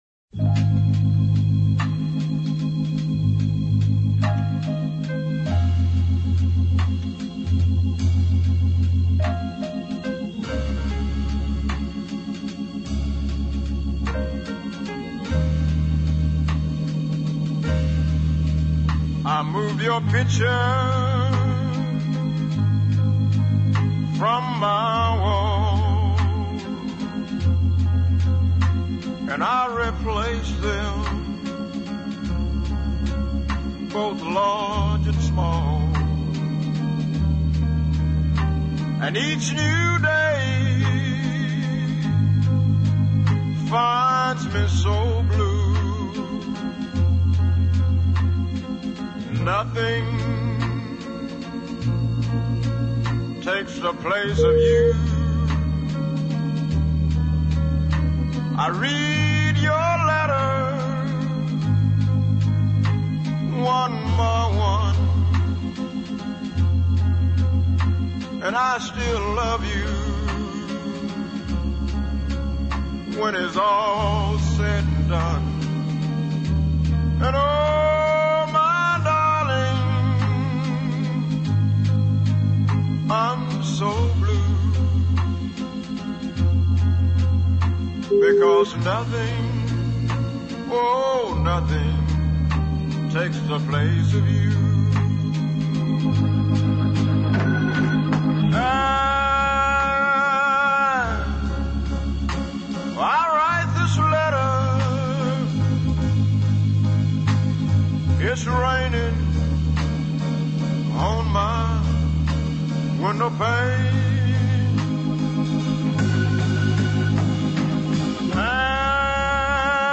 rich, dark baritone voice